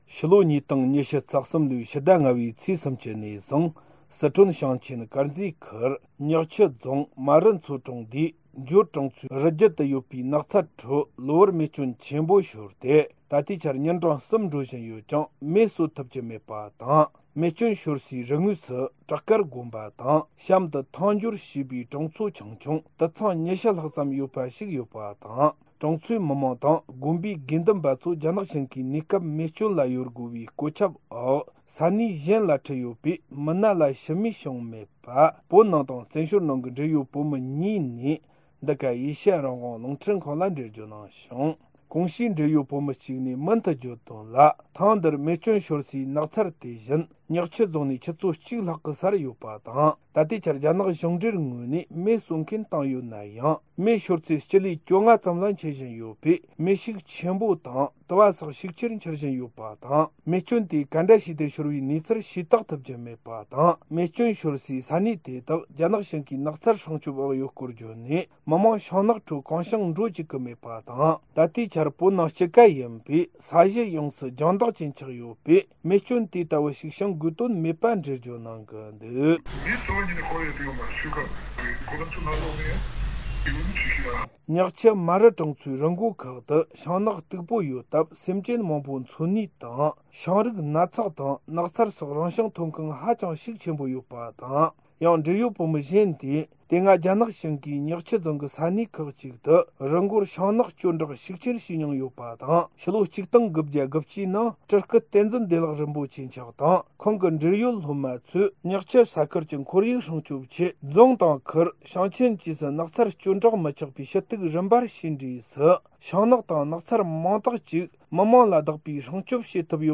སྒྲ་ལྡན་གསར་འགྱུར།
ཕྱི་ལོ་༢༠༢༣ལོའི་ཕྱི་ཟླ་༥ཚེས་༣༠ནས་བཟུང་སི་ཁྲོན་ཞིང་ཆེན་དཀར་མཛེས་ཁུལ་ཉག་ཆུ་རྫོང་མར་རུ་མཚོ་གྲོང་སྡེའི་འཇོར་གྲོང་ཚོའི་རི་རྒྱུད་དུ་ཡོད་པའི་ནགས་ཚལ་ཁྲོད། གློ་བུར་མེ་སྐྱོན་ཆེན་པོ་ཤོར་ཏེ། ད་ལྟའི་ཆར་ཉིན་གྲངས་གསུམ་འགྲོ་བཞིན་ཡོད་ཀྱང་མེ་གསོད་ཐུབ་ཀྱི་མེད་པ་དང་། མེ་སྐྱོན་ཤོར་སའི་རི་ངོས་སུ་བྲག་དཀར་དགོན་པ་དང་གཤམ་དུ་ཐང་འཇོར་ཞེས་པའི་གྲོང་ཚོ་ཆུང་ཆུང་དུད་ཚང་༢༠ལྷག་ཡོད་པ་ཞིག་དང་། གྲོང་ཚོའི་མི་མང་དང་དགོན་པའི་ནང་གི་དགེ་འདུན་པ་ཚོ་རྒྱ་ནག་གཞུང་གིས་གནས་སྐབས་མེ་སྐྱོན་ལ་གཡོལ་དགོས་པའི་བཀོད་ཁྱབ་འོག་ས་གནས་གཞན་ལ་ཁྲིད་ཡོད་པས། མི་སྣ་ལ་ཤི་རྨས་བྱུང་མེད་པའི་སྐོར། བོད་ནང་དང་བཙན་བྱོལ་ནང་གི་འབྲེལ་ཡོད་བོད་མི་གཉིས་ནས་འདི་ག་ཨེ་ཤེ་ཡ་རང་དབང་རླུང་འཕྲིན་ཁང་ལ་འགྲེལ་བརྗོད་གནང་བྱུང་།